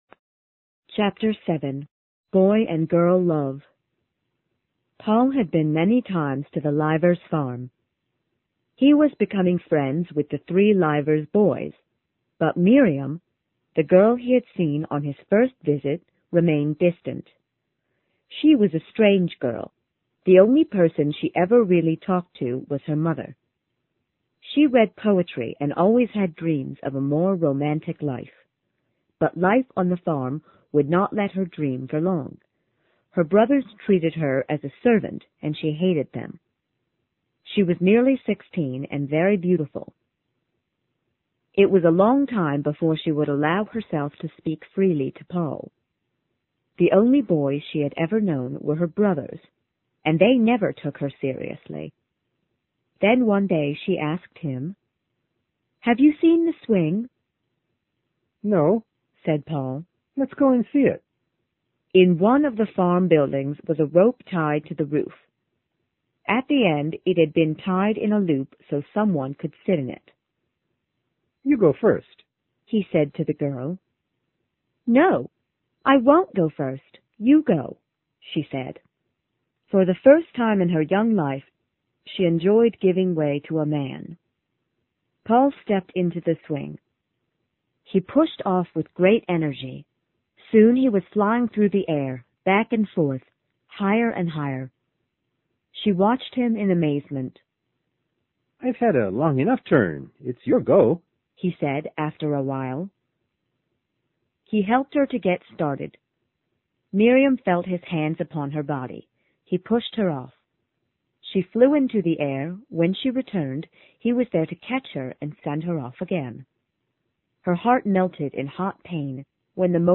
在线英语听力室有声名著之儿子与情人 Chapter7的听力文件下载,有声名著－在线英语听力室